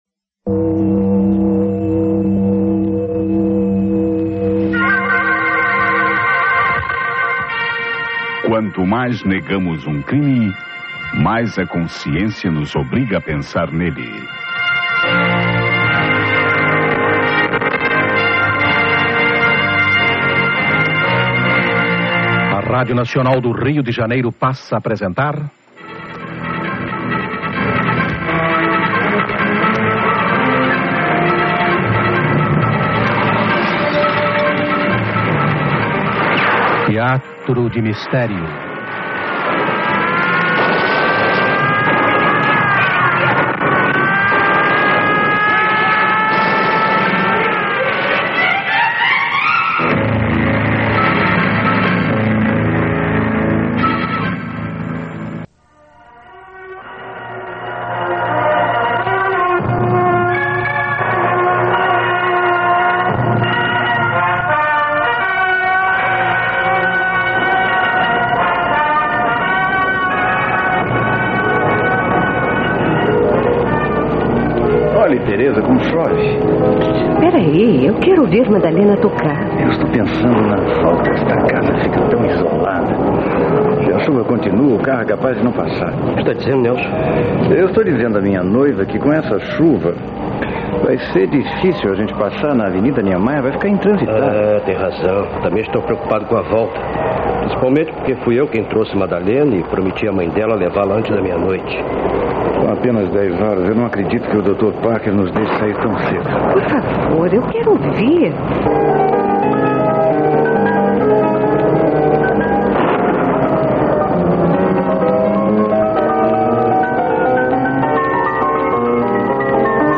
Produzido entre os anos 70 e inicio dos 80, o "Teatro de Mistério" marcou positivamente o radioteatro, que na época já se encontrava em total ocaso em virtude da popularização crescente da TV.